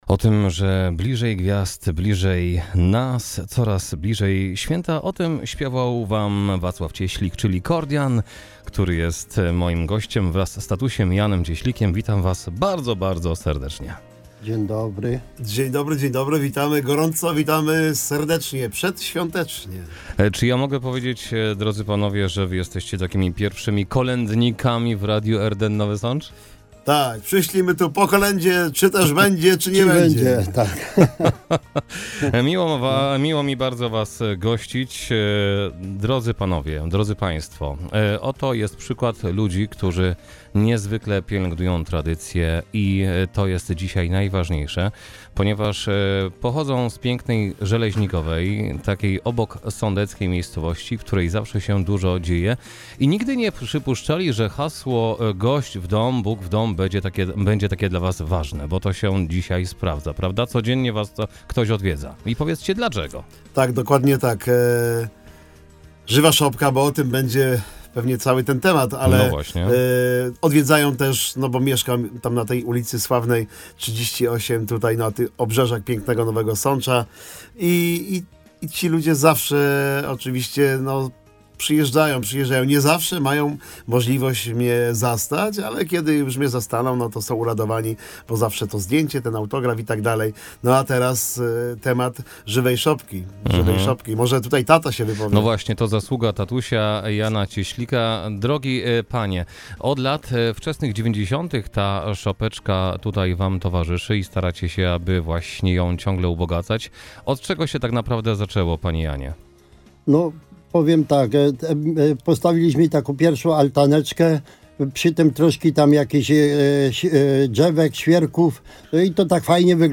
Żywa Szopka w Żeleźnikowej Wielkiej ruszy w Boże Narodzenie [ROZMOWA]